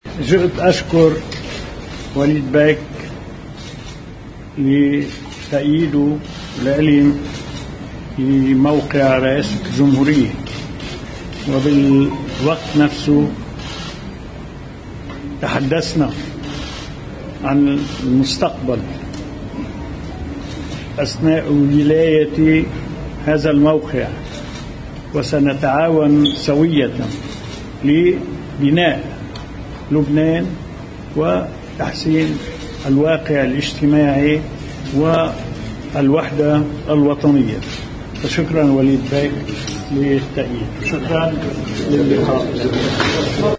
بالصوت العماد عون من كليمنصو: اشكر جنبلاط على تأييده (audio)